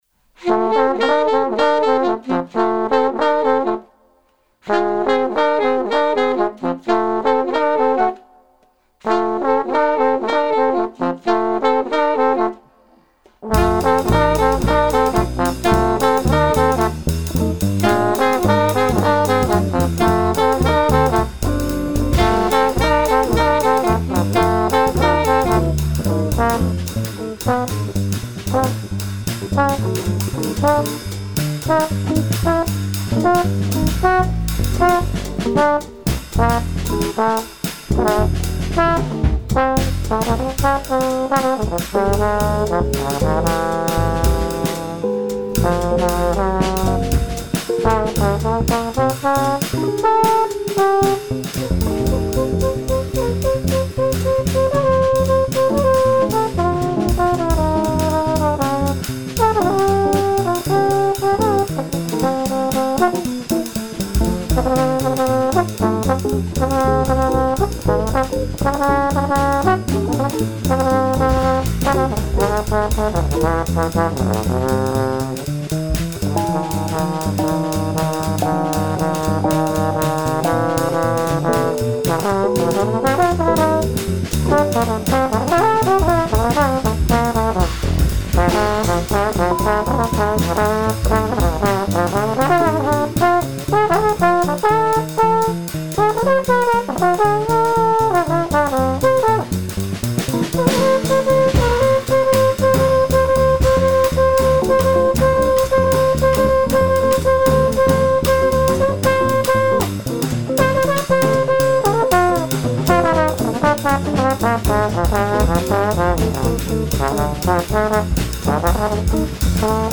sax
trombone
guitar
bass
drums